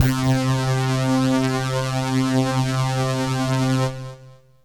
SYNTH LEADS-1 0007.wav